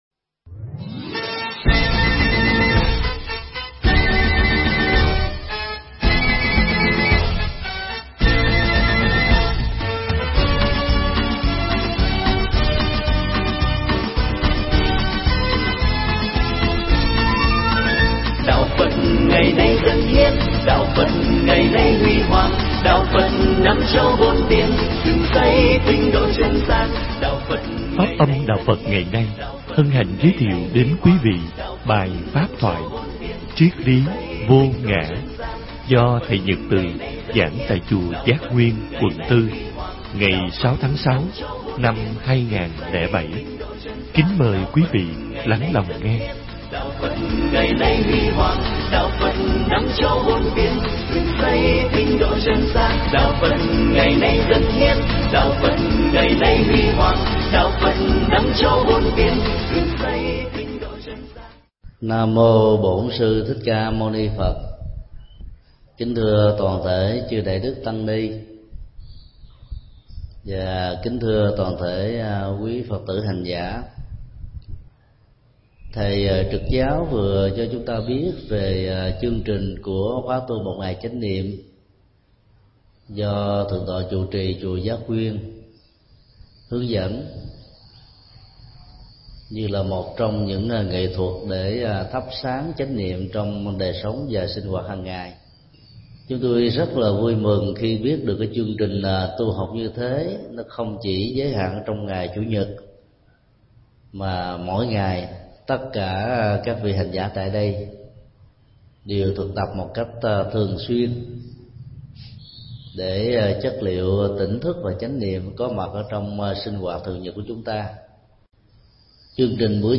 thuyết pháp Triết lý vô ngã
giảng tại Chùa Giác Nguyên